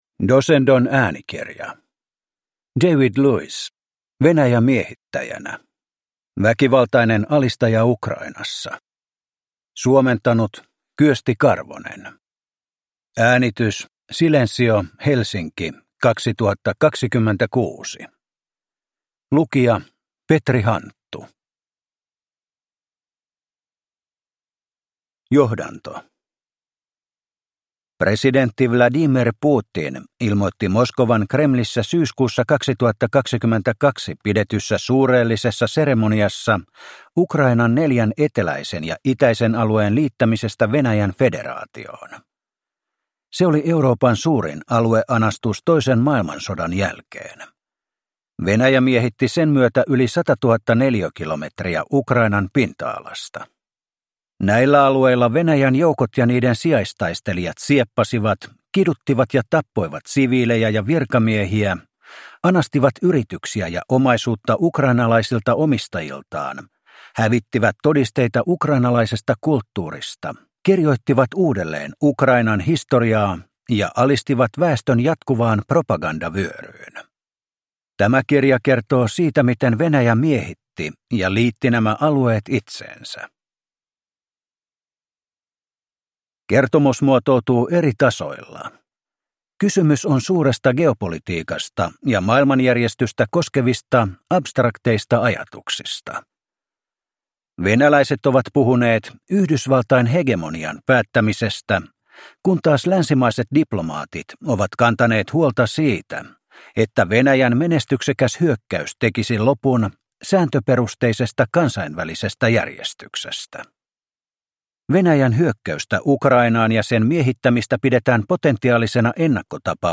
Venäjä miehittäjänä – Ljudbok